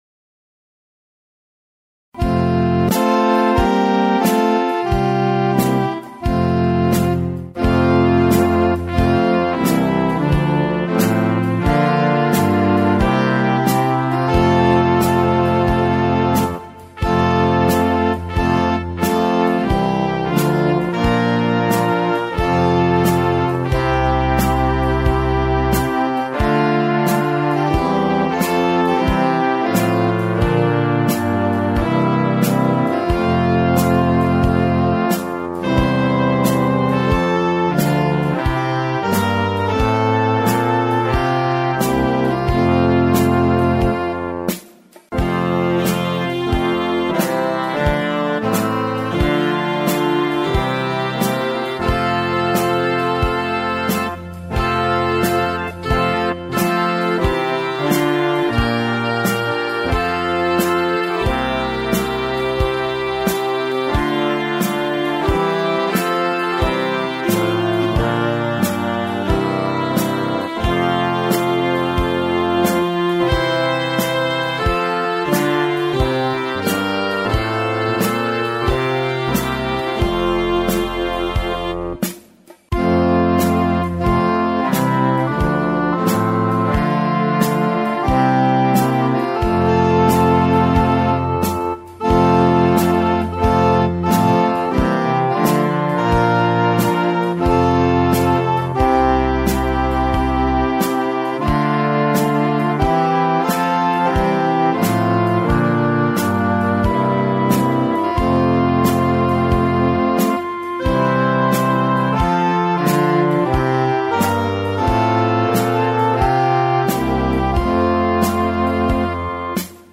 [This accompaniment includes a prelude]
Words: Isaac Watts